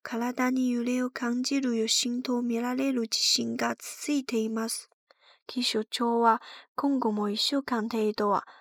Voz AI de garota japonesa autêntica
Dê vida aos seus personagens e conteúdo com uma voz jovem e expressiva de garota japonesa.
Texto para fala
Cadência natural
Kawaii e energética
Desenvolvida com síntese neural avançada, esta voz fornece o equilíbrio perfeito entre energia juvenil e clareza profissional.